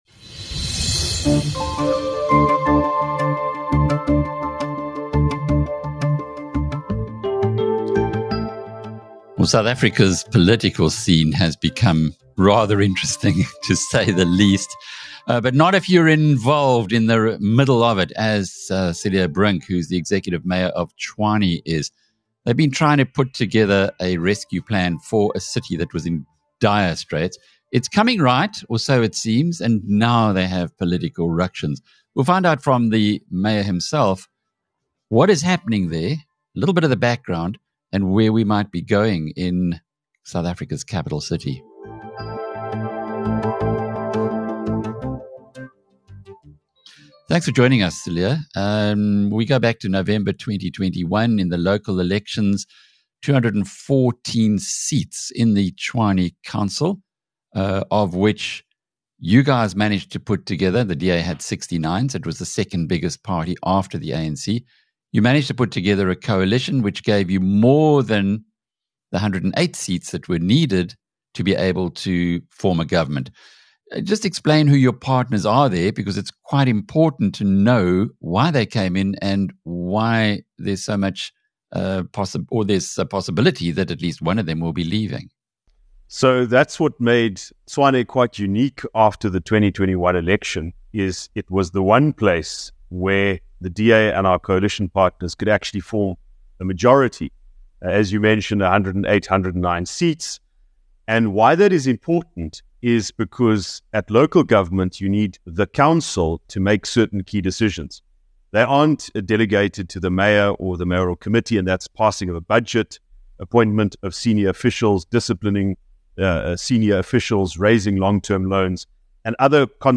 Not quite a year and a half into recovery, the Tshwane Metro is again on the brink of descending into chaos. In this interview, Tshwane’s executive mayor, Cilliers Brink, unpacks why this is happening. He says the national leaders of Action SA, specifically its founder/president Herman Mashaba, are imposing their will on the party’s members in the capital city.